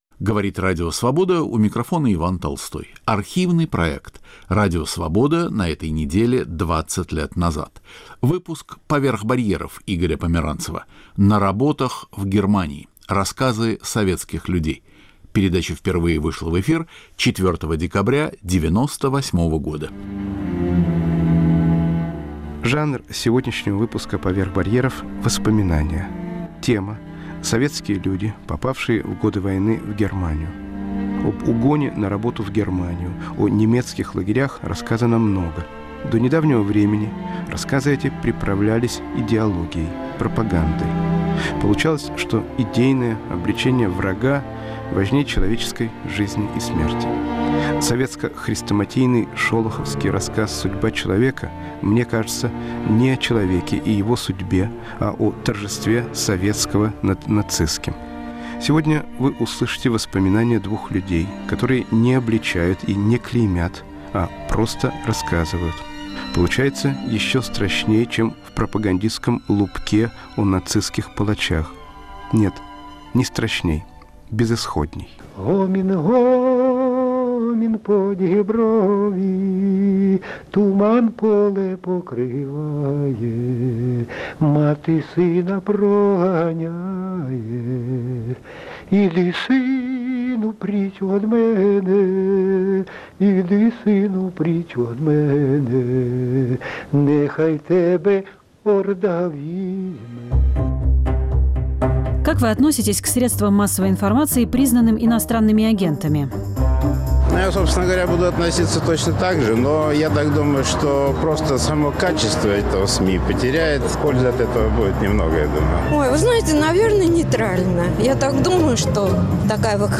Архивный проект.